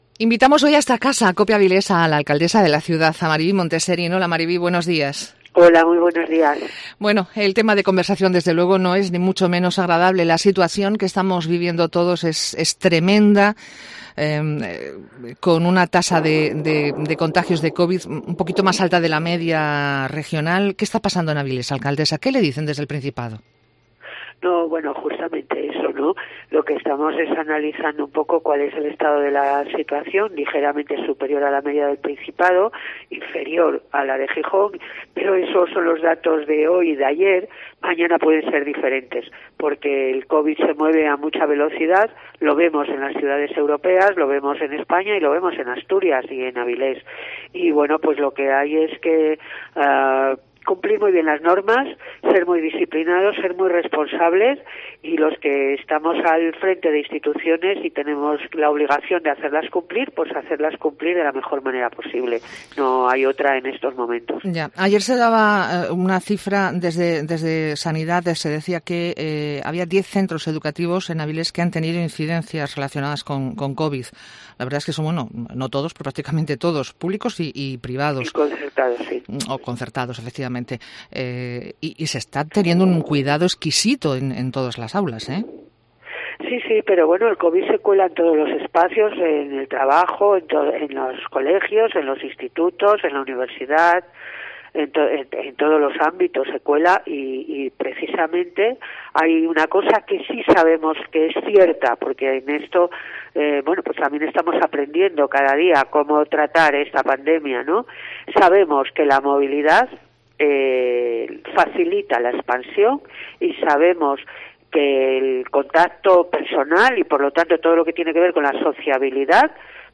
Entrevista con Mariví Monteserín
Hemos charlado con ella en el MEDIODÍA Cope sobre el decreto de cierre de actividad y sus consecuencias.